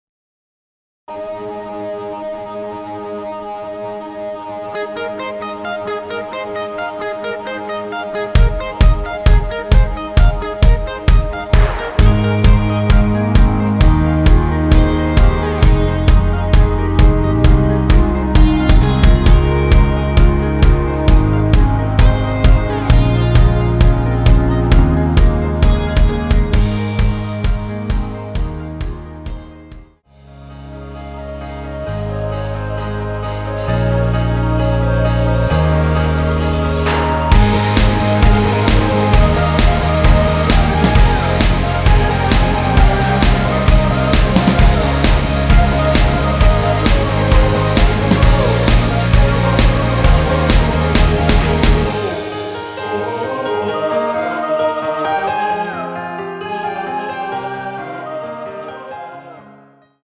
원키에서(-1)내린 멜로디 포함된 MR입니다.(미리듣기 확인)
Ab
◈ 곡명 옆 (-1)은 반음 내림, (+1)은 반음 올림 입니다.
앞부분30초, 뒷부분30초씩 편집해서 올려 드리고 있습니다.
중간에 음이 끈어지고 다시 나오는 이유는